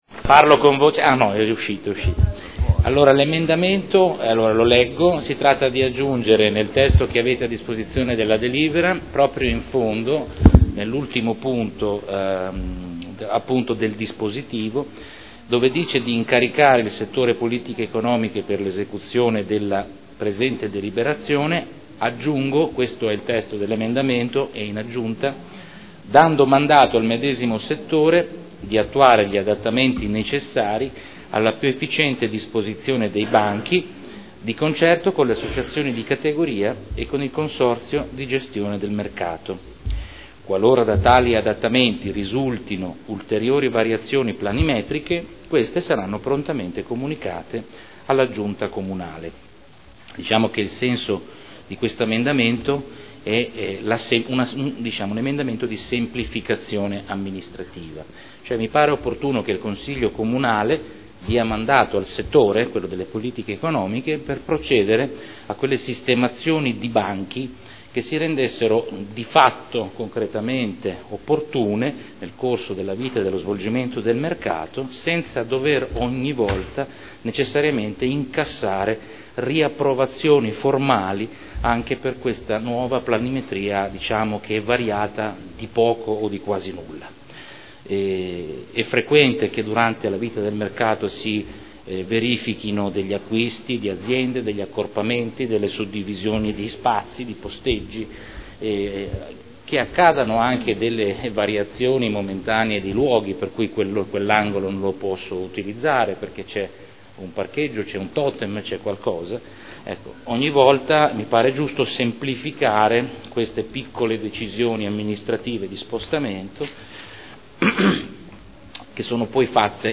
Salvatore Cotrino — Sito Audio Consiglio Comunale
Seduta del 12/12/2013 Proposta di deliberazione: Mercato settimanale del lunedì – Variazione posteggi . Presentazione emendamento da parte del consigliere Cotrino